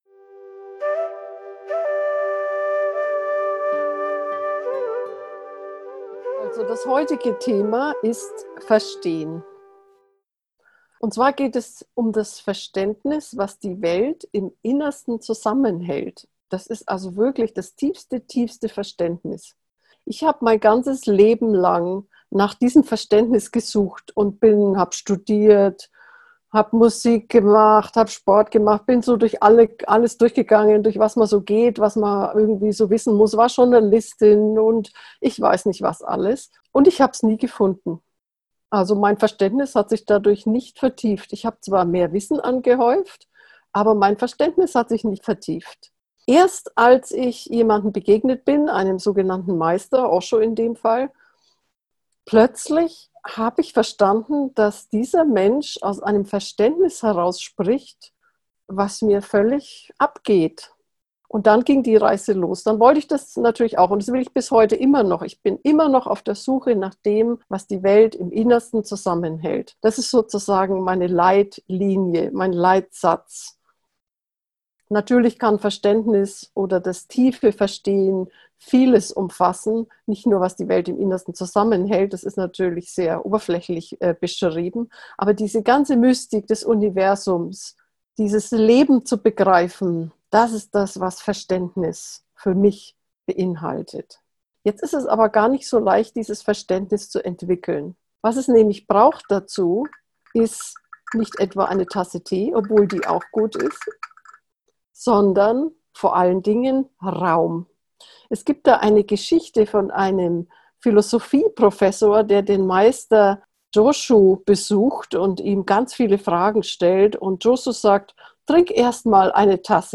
Meditationsanleitung zur geführten Meditation
verstehen-dankbarkeit-meditation